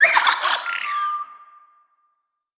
Sfx_creature_seamonkey_taunt_02.ogg